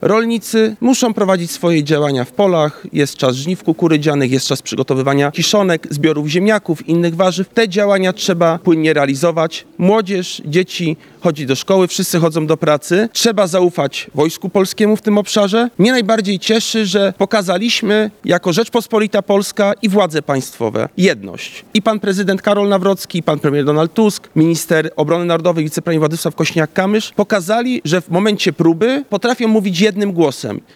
– To dla nas wszystkich nowa sytuacja, ale nie możemy wprowadzać paniki – mówi podsekretarz stanu w Ministerstwie Rolnictwa i Rozwoju Wsi, Adam Nowak.